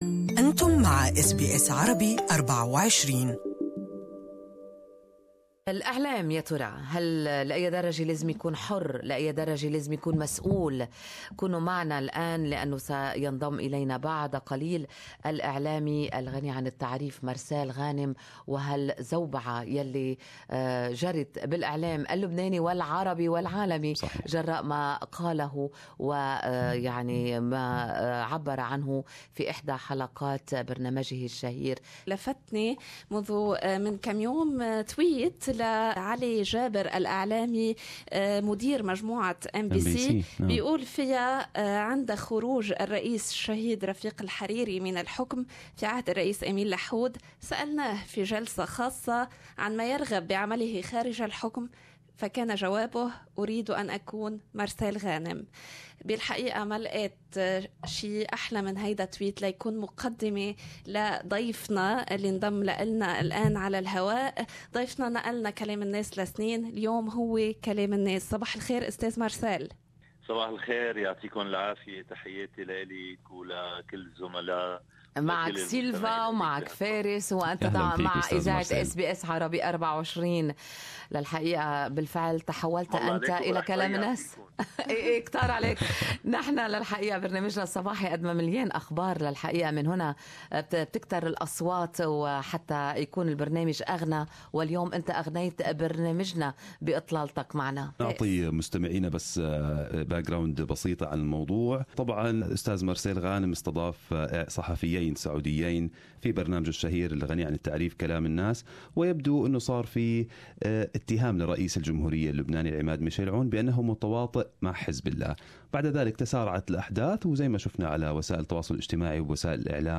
Interview with the prominent Lebanese TV presenter Marcel Ghanem.